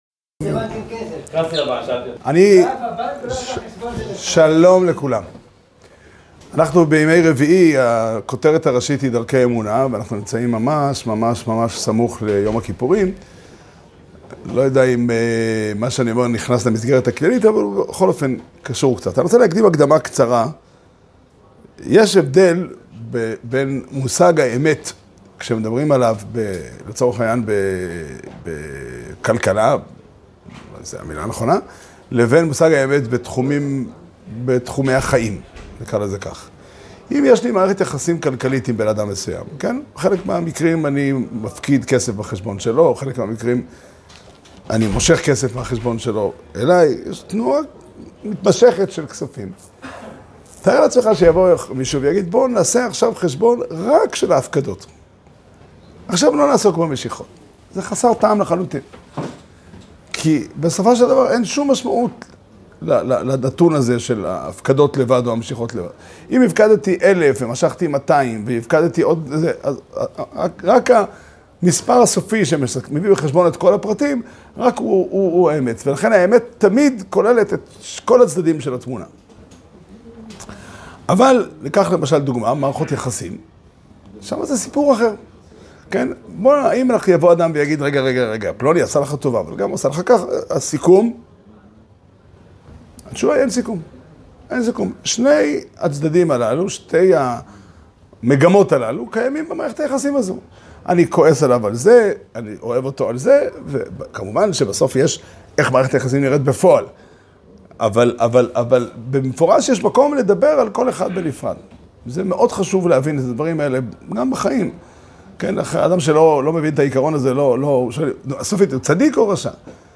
שיעור שנמסר בבית המדרש פתחי עולם בתאריך ז' תשרי תשפ"ה